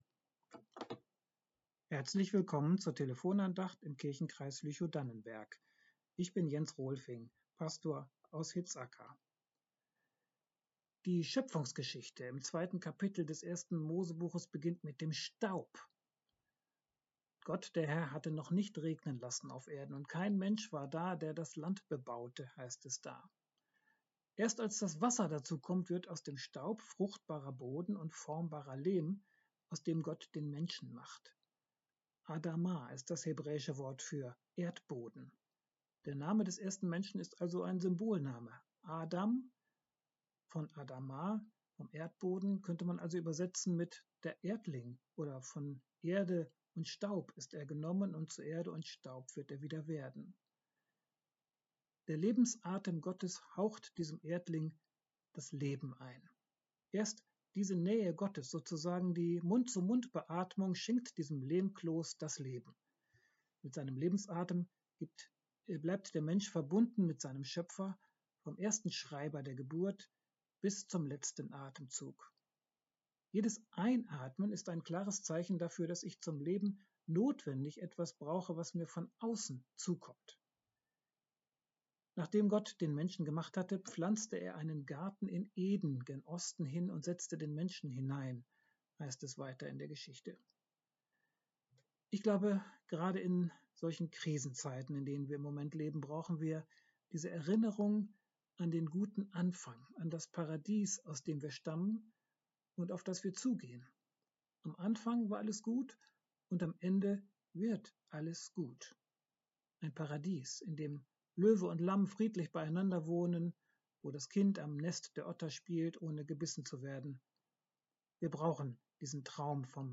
Telefon-Andacht